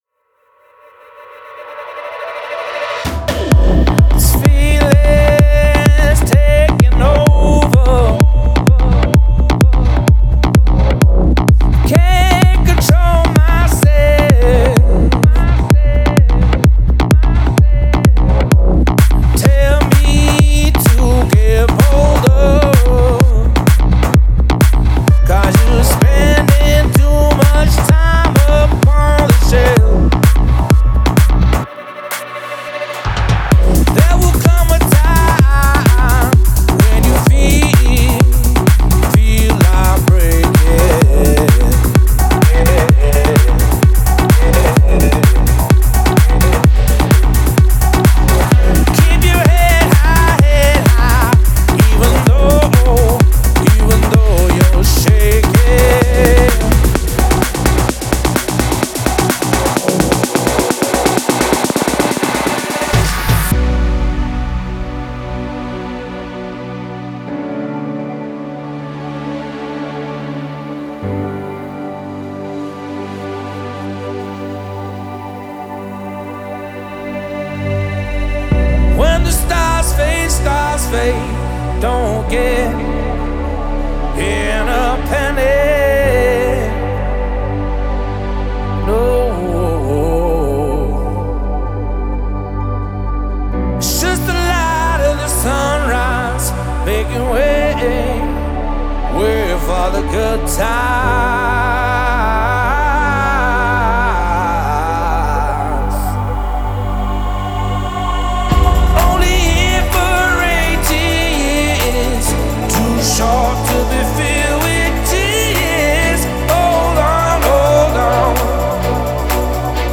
это трек в жанре прогрессивного house